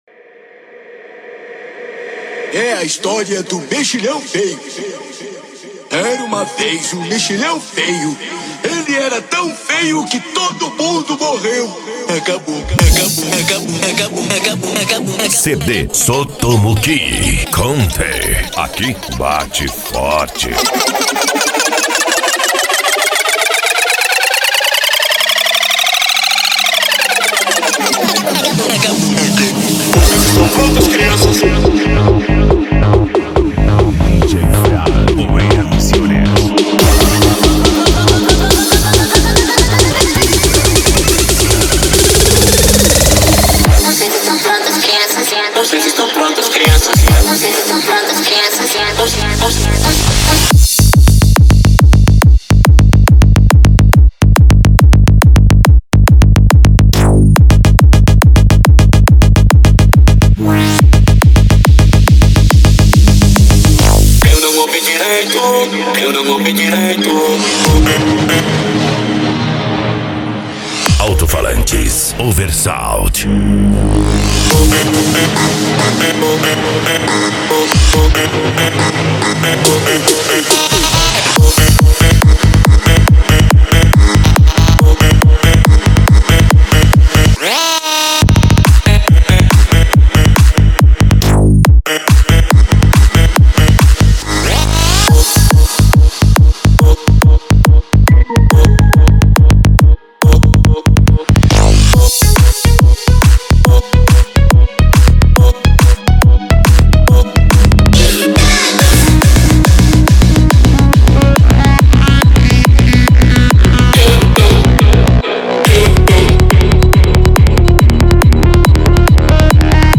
japan music